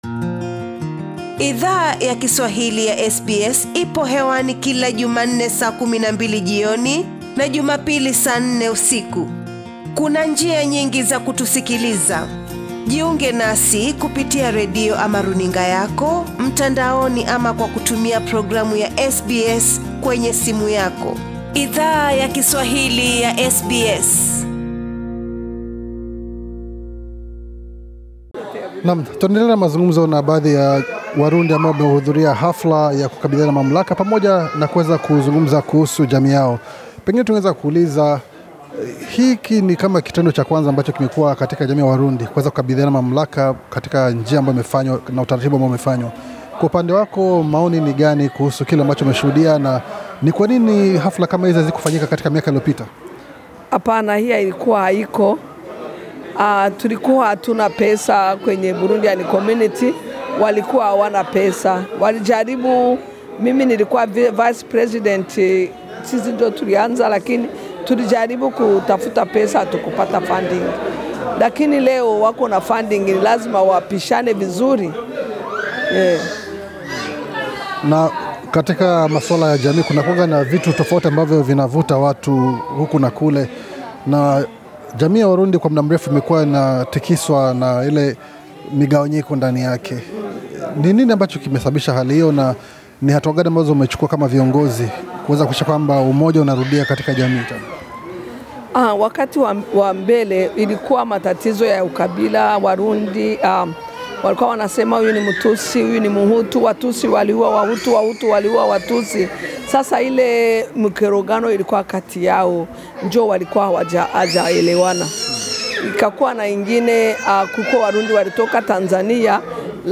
Idhaa ya Kiswahili ilizungumza na baadhi ya wanachama wa jamii yawarundi mjini Sydney, walio hudhuria sherehe yaku kabidhi mamla viongozi wapya wa jamii hiyo. Wanachama hao walitueleza ujuzi ambao wanahisi kiongozi mzuri anastahili kuwa nao, kwa ajili yakuongoza jamii au shirika lolote kwa ufanisi.